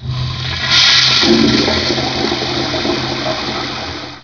Toilet01
TOILET01.WAV